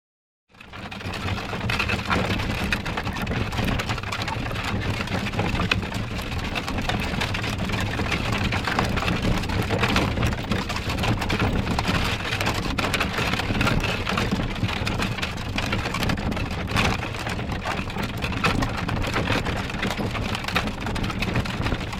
Здесь собраны натуральные записи: от мерного цокота копыт до характерного скрипа деревянных осей.
Звук колесницы движущейся по ухабистой дороге